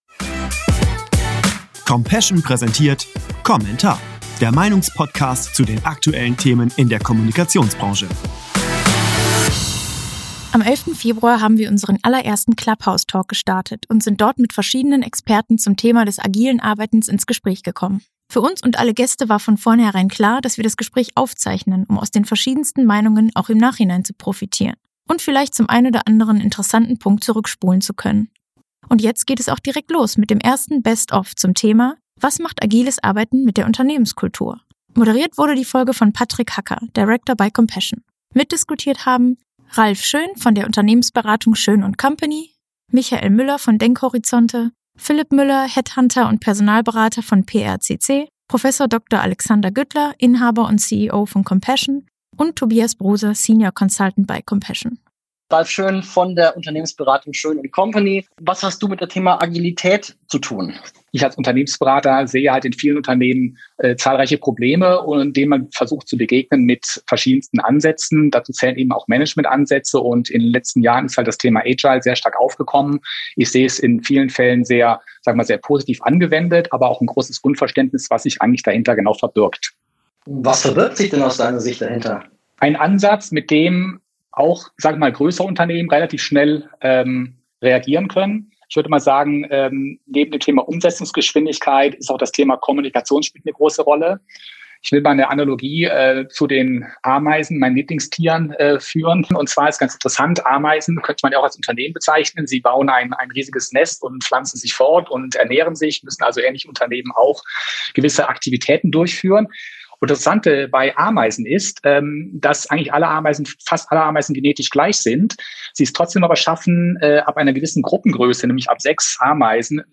#07 komm.passion-Clubhouse-Talk zu Agilem Arbeiten ~ komm.entar Podcast